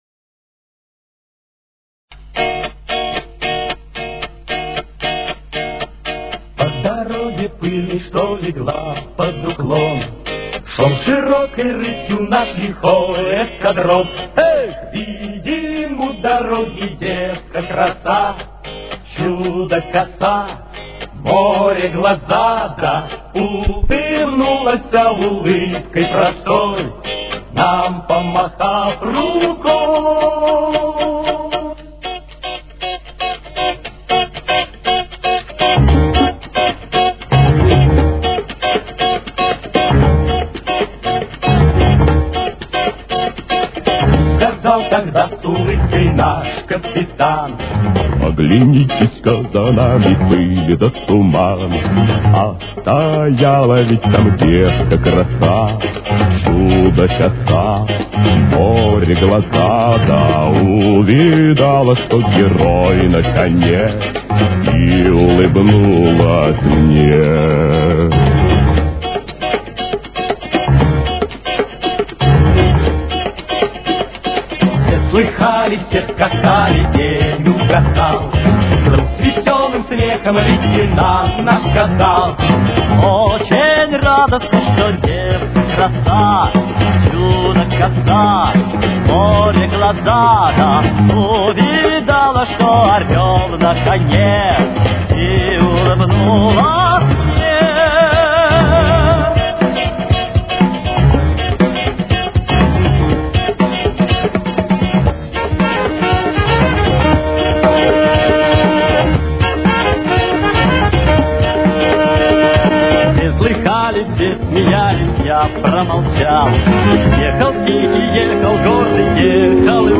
Тональность: Ми-бемоль минор. Темп: 118.